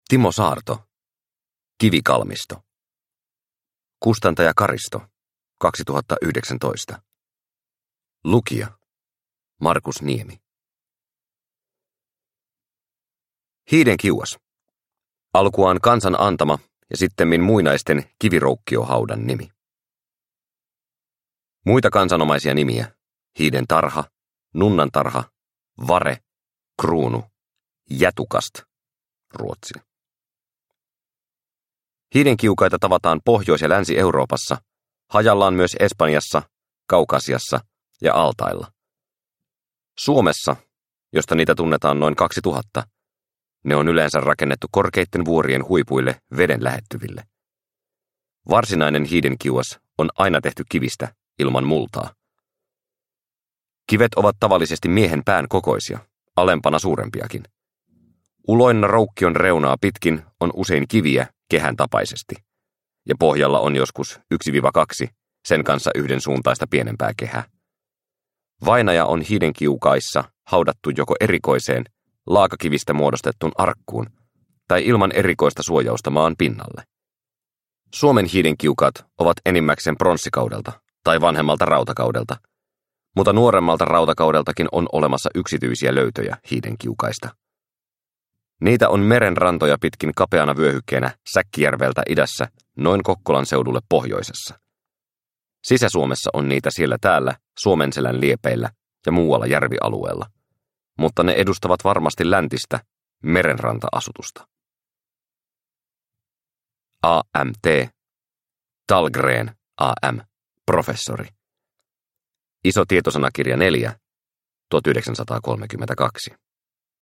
Kivikalmisto – Ljudbok – Laddas ner